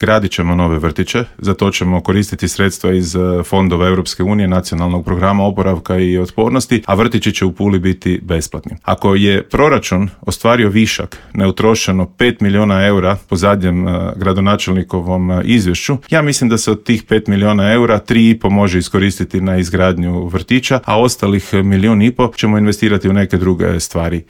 Borba se vodi i u Gradu Puli gdje poziciju čelnog čovjeka želi bivši predsjednik SDP-a i saborski zastupnik Peđa Grbin koji je u Intervjuu Media servisa poručio: